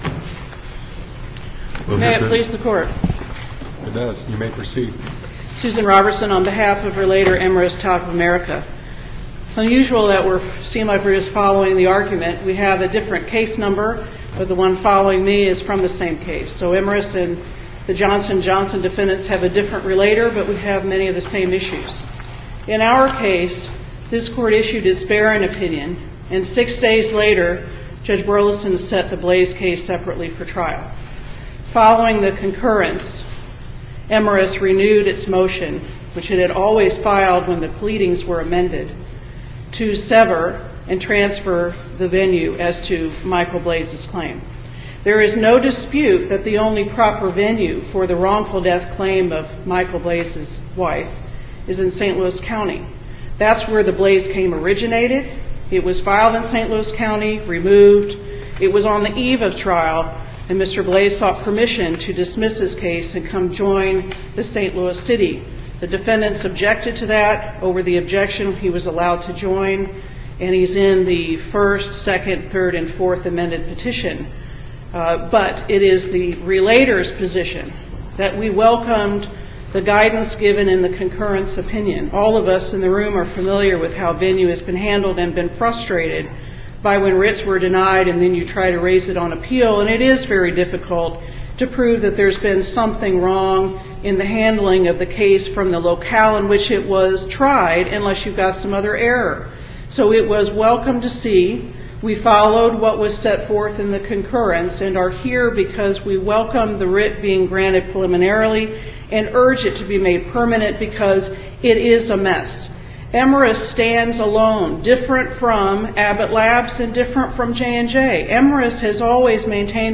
MP3 audio file of arguments in SC96718